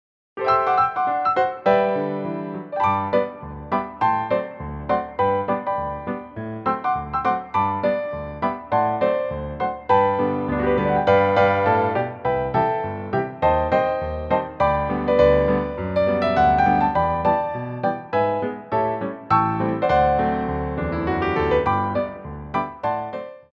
Warm Up Jumps 2
2/4 (16x8)